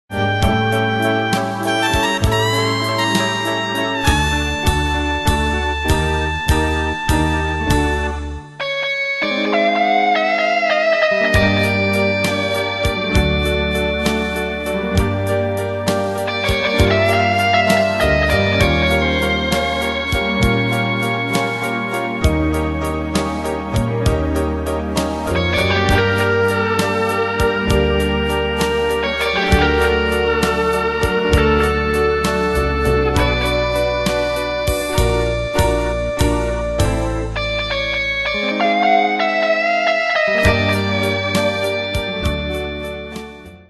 Style: Oldies Ane/Year: 1962 Tempo: 132 Durée/Time: 3.46
Danse/Dance: Ballade Cat Id.
Pro Backing Tracks